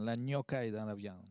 Localisation Saint-Hilaire-de-Riez
Catégorie Locution